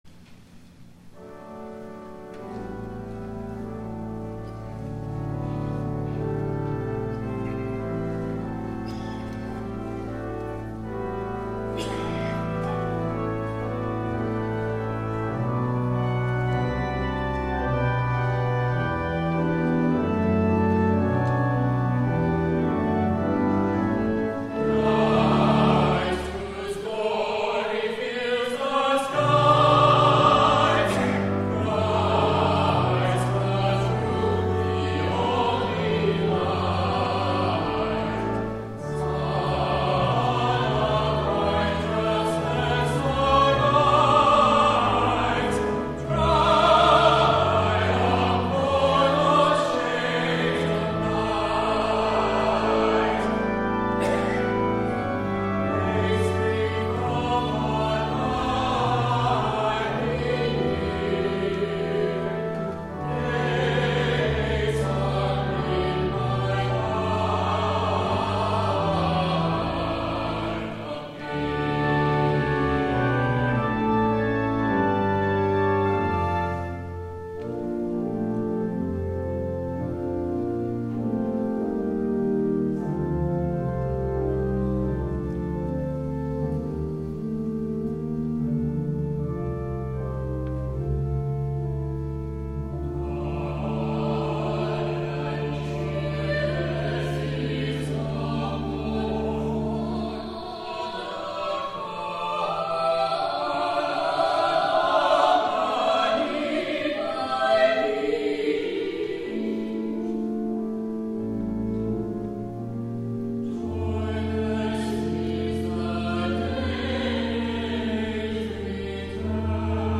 THE ANTHEM
soprano
alto
tenor
baritone